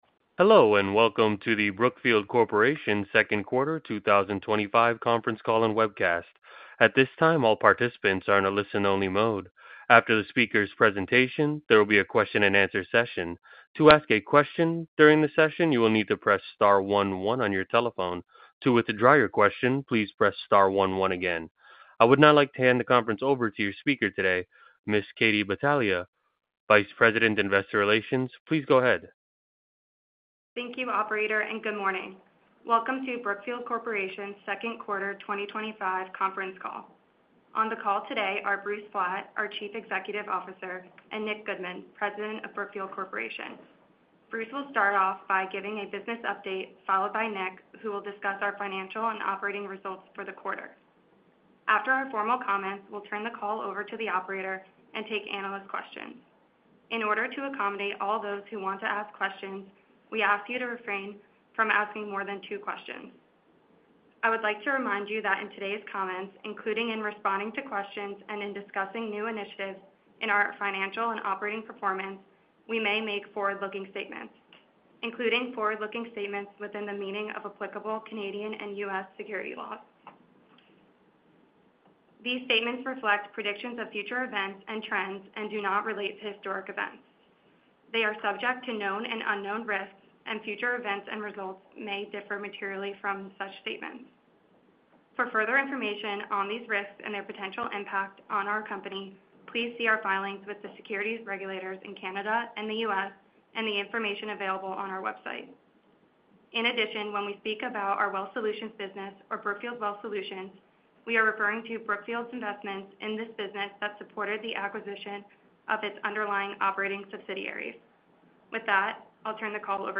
brookfield-corporation-2025-q2-conference-call-and-webcast.mp3